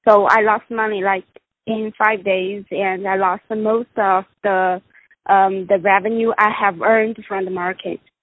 A NEW INVESTOR IN THE CHINESE STOCK MARKET, SPEAKS ABOUT HOW MUCH SHE LOST IN SO SHORT A TIME.